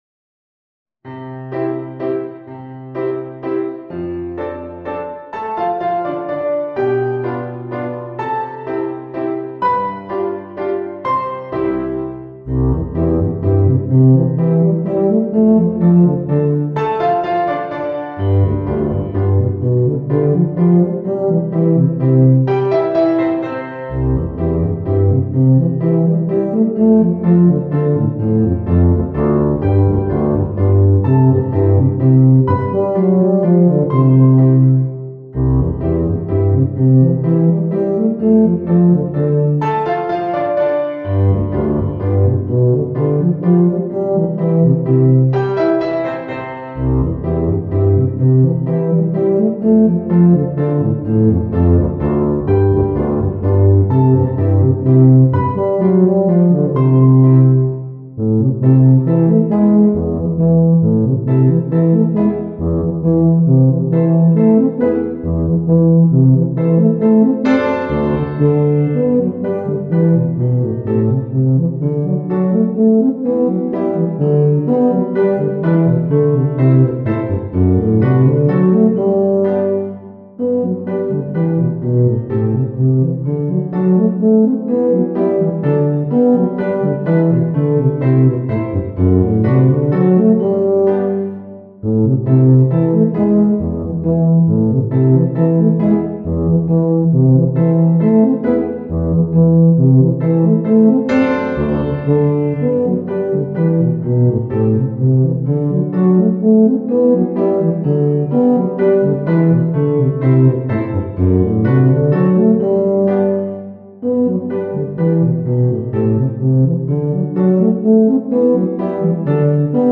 ピアノ付き
Tuba / Basse (Solo), Bassoon (Solo), Eb Bass (Solo)
クラシック音楽
Piano accompaniment